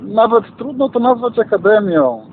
Mówi insp. Paweł Szyperek – komendant powiatowy w Ełku